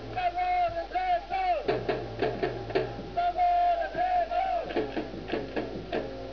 オ ー レ ヨ コ ハ マ の 応 援
「オーレ ヨコハマ」の応援は、基本的に太鼓１つでも出来る「声」による簡単なパターンになっています。